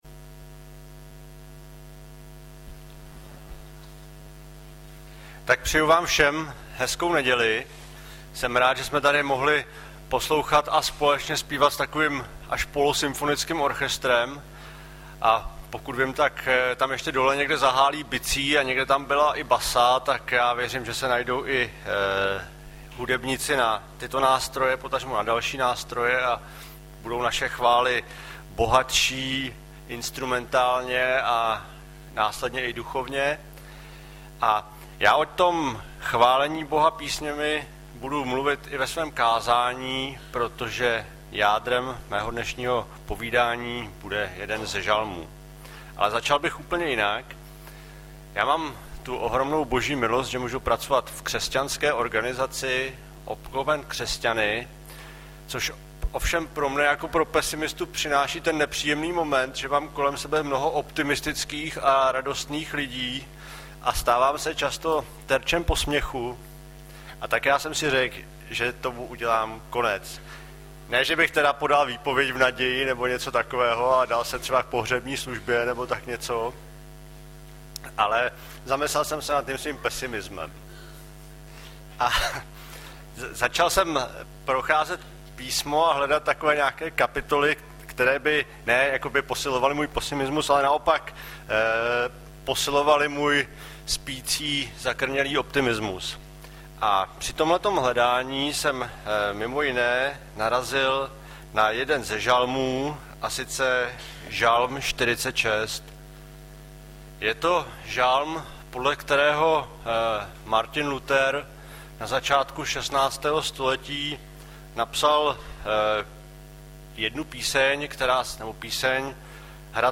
Kázání